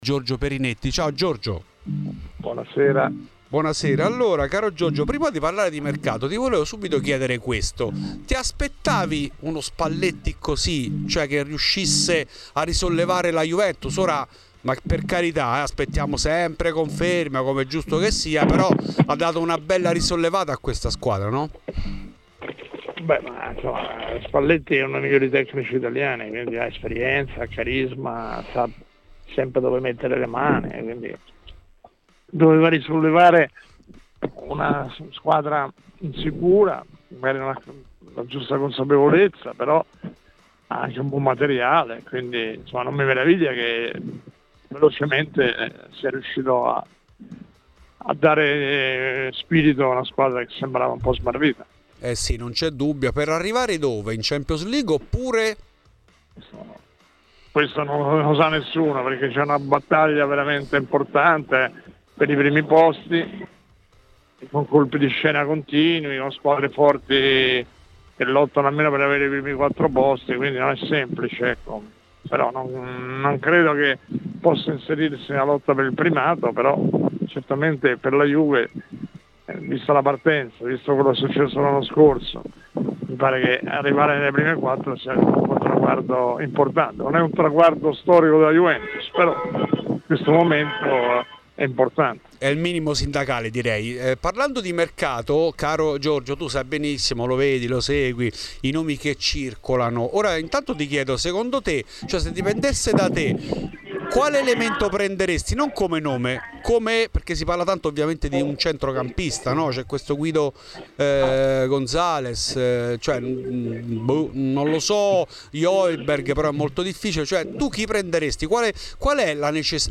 Intervenuto ai microfoni di Radio Bianconera durante il programma ''Fuori di Juve'', il direttore sportivo Giorgio Perinetti, si è espresso sui temi del giorno in casa della Vecchia Signora.